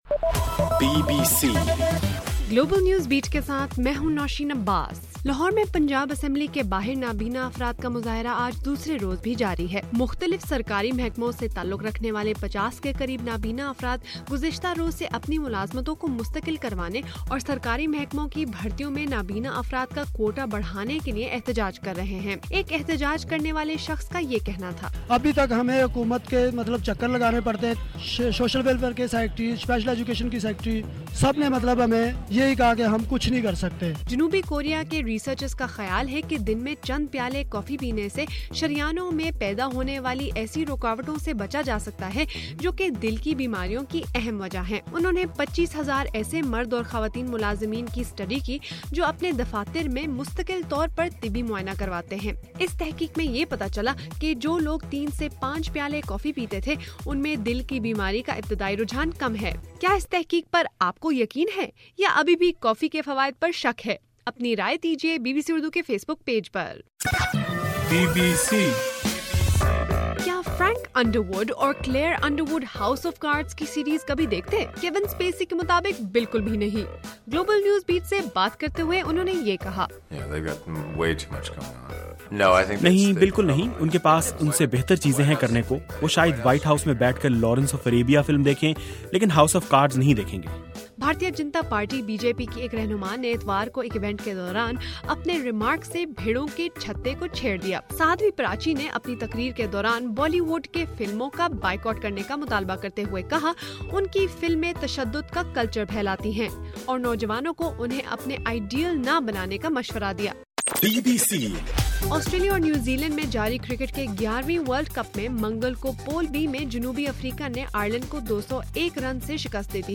مارچ 3: رات 10 بجے کا گلوبل نیوز بیٹ بُلیٹن